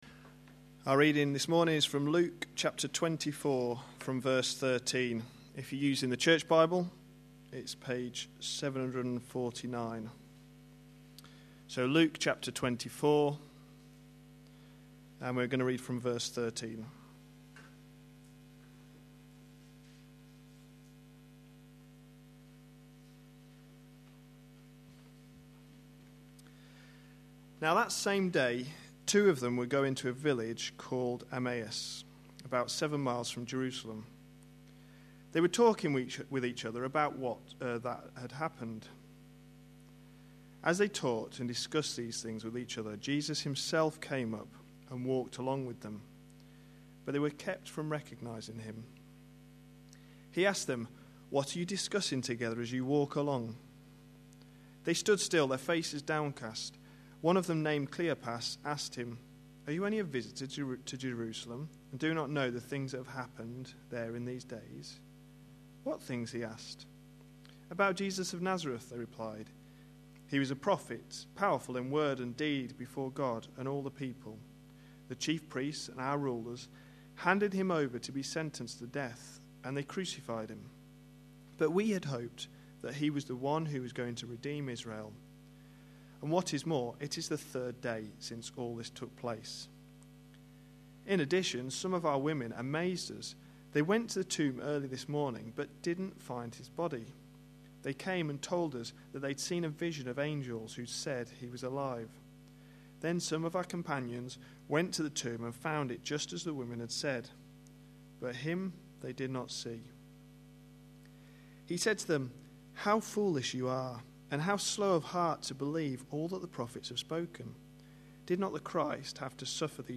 A sermon preached on 13th September, 2011, as part of our Distinctives series.